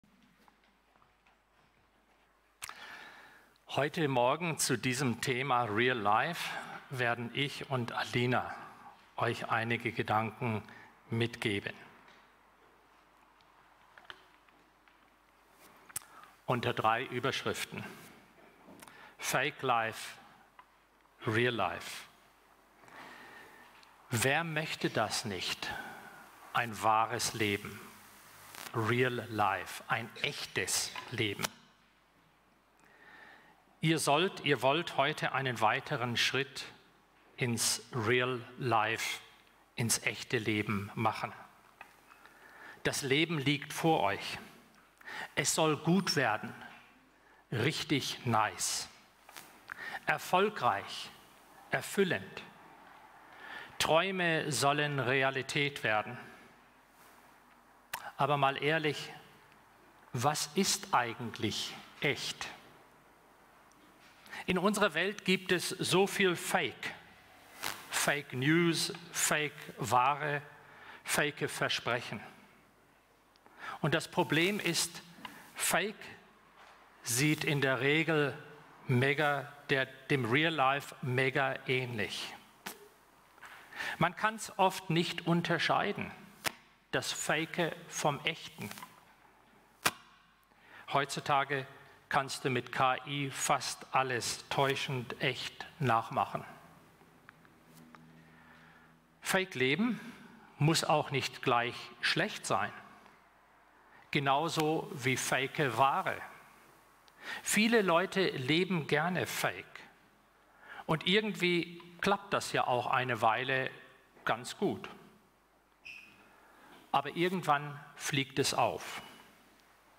Real Life – Predigt zum Unti Abschluss 2025 › Viva Kirche Grüningen
Untiabschluss-Gottesdienst_-Real-Life.mp3